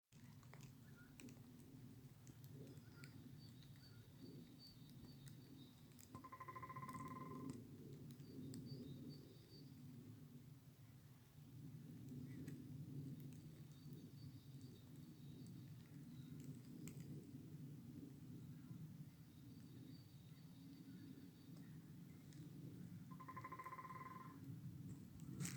Birds -> Woodpeckers ->
White-backed Woodpecker, Dendrocopos leucotos
StatusSinging male in breeding season